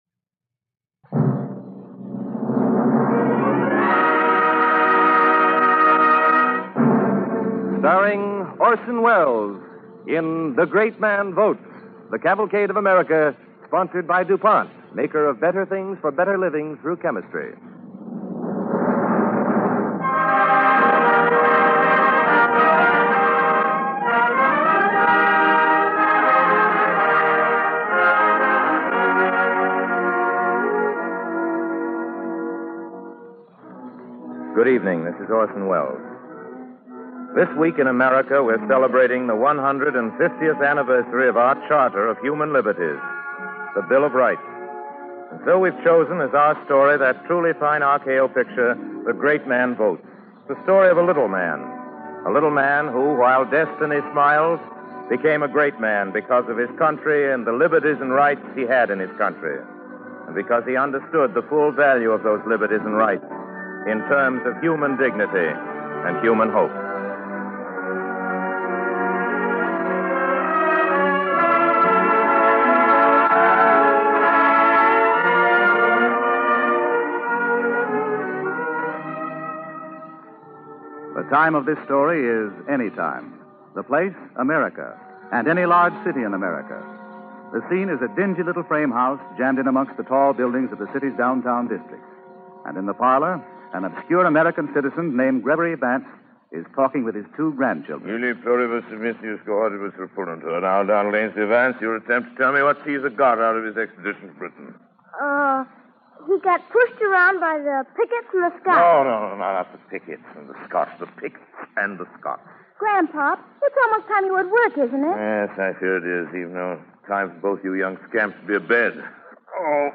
starring Orson Welles
Cavalcade of America Radio Program